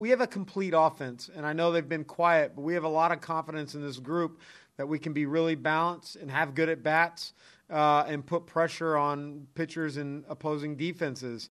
Kevin Cash, Tampa Bay’s manager, spoke after the game about the confidence he has in his lineup this series.